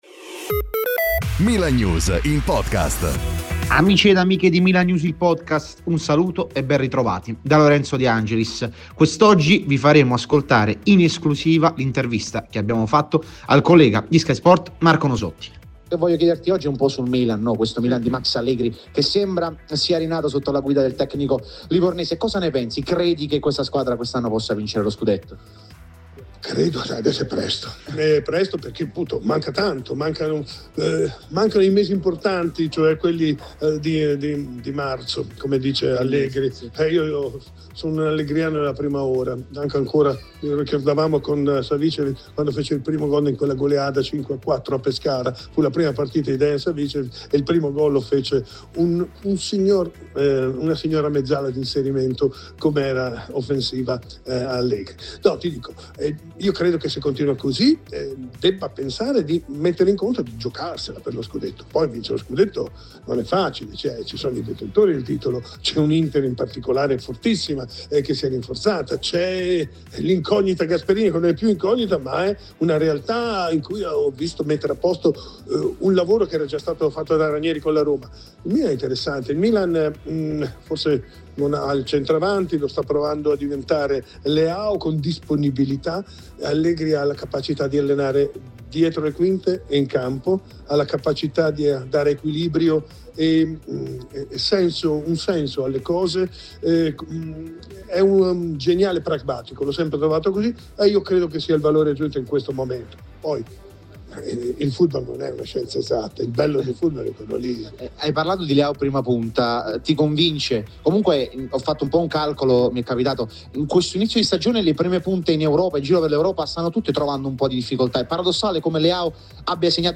esclusiva mn